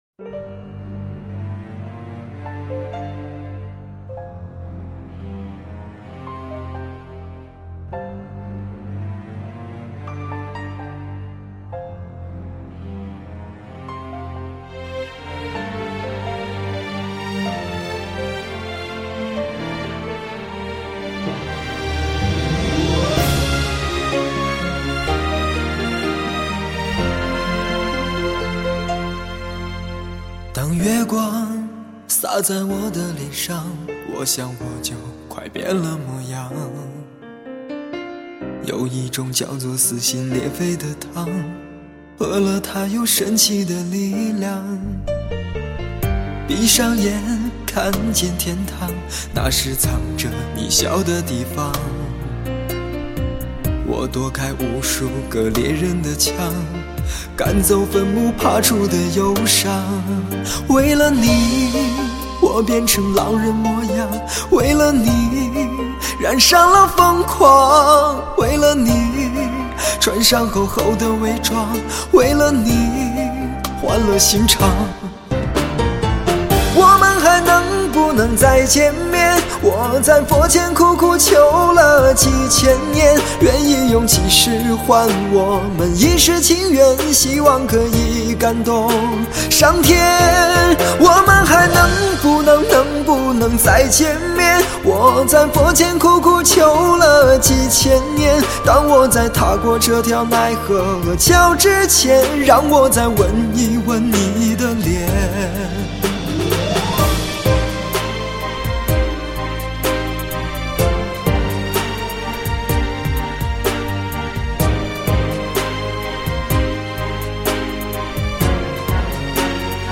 二胡
琵琶
古筝
风笛、箫
大提琴
小提琴
吉它